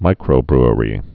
(mīkrō-brə-rē, -brrē)